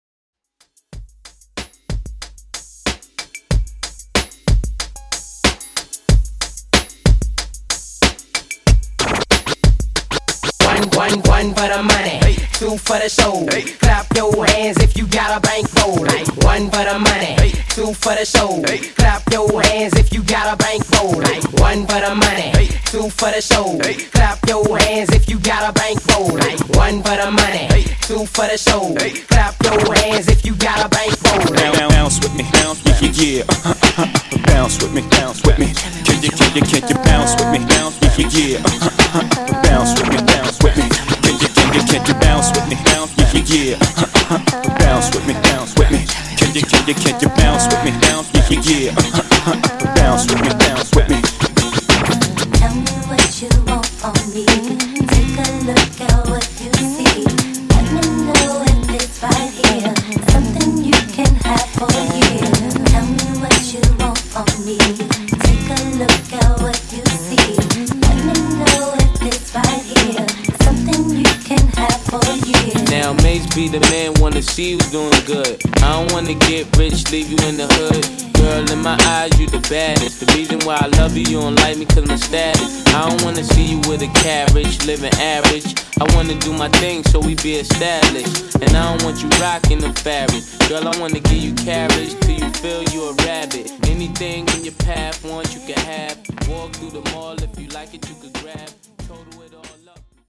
90s RnB ReDrum)Date Added